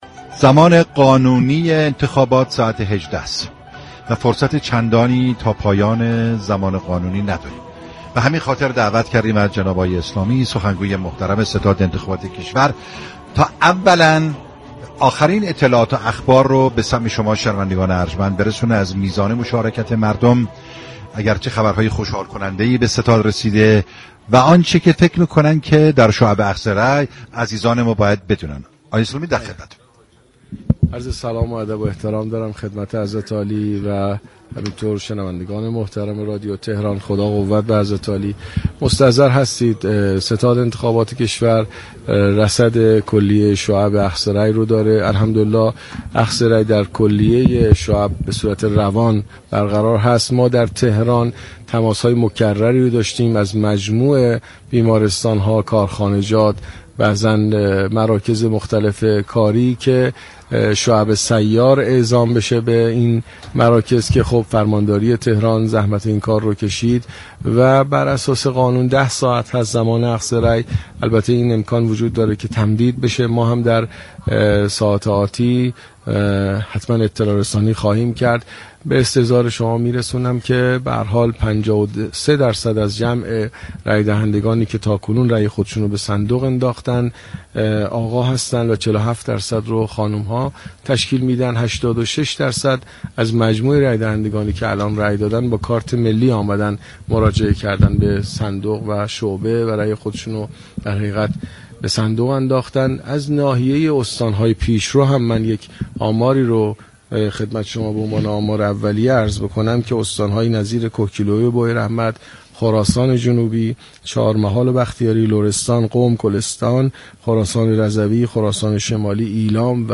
گفت و گو با ویژه برنامه انتخاباتی «شهرآرا»